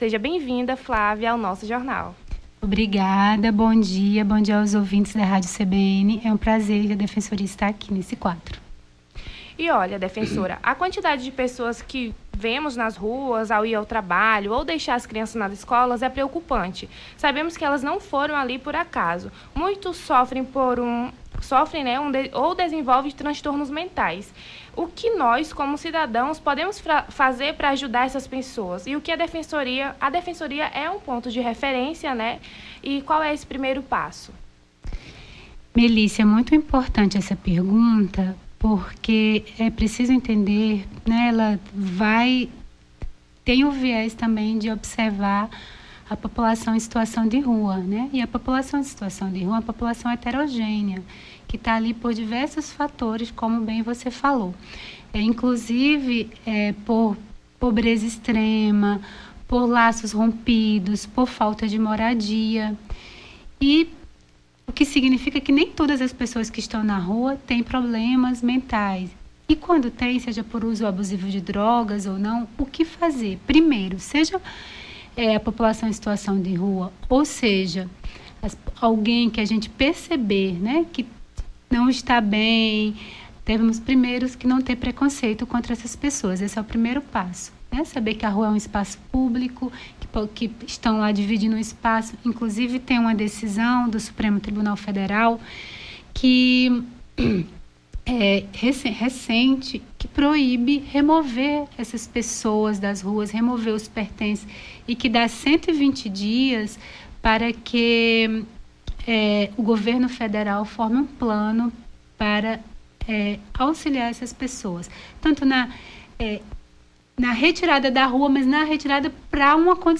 Nome do Artista - CENSURA - ENTREVISTA (DEFENSORIA PUBLICA) 07-08-23.mp3